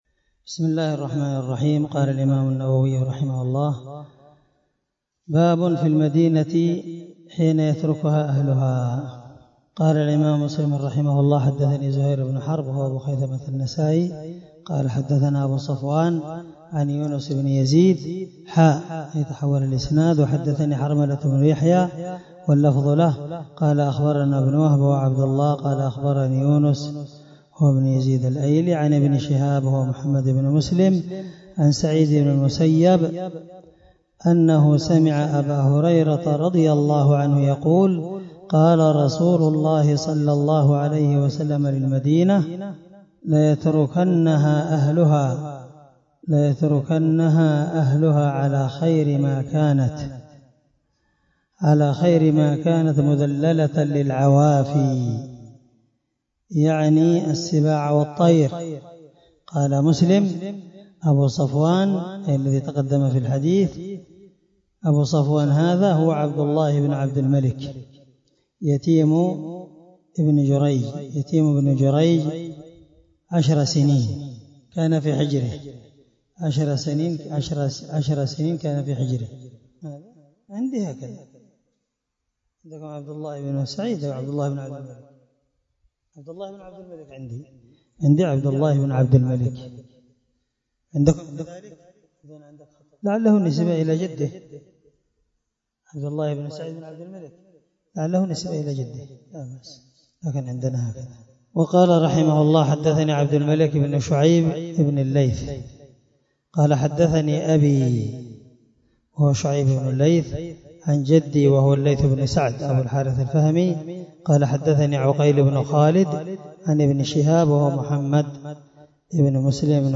الدرس98من شرح كتاب الحج حديث رقم(1389) من صحيح مسلم